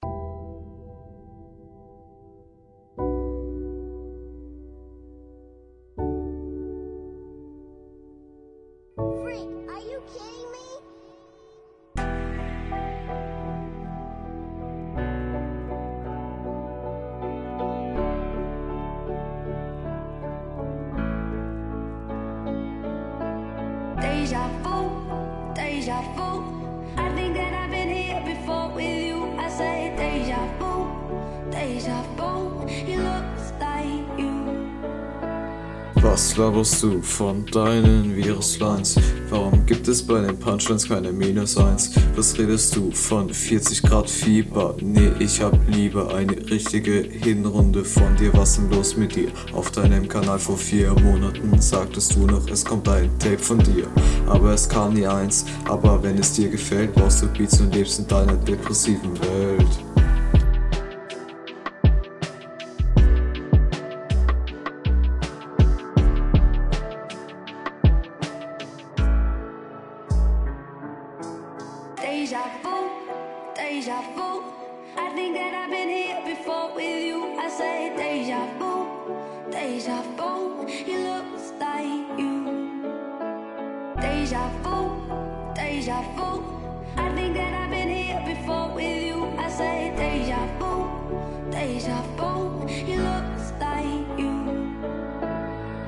Flow: ➨ Dein Flow kommt hier bisschen schlechter, als der deines Gegners. ➥ ➥ Hätte …